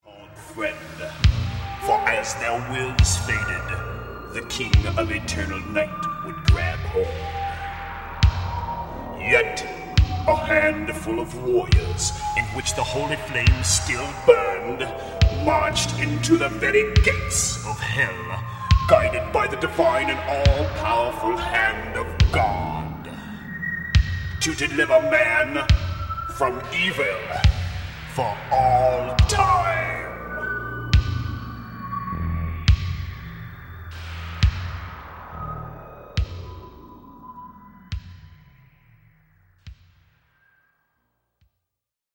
Category: Hard Rock
drums, percussion
lead guitar
bass, backing vocals
lead vocals, rthythm guitar
keyboards, backing vocals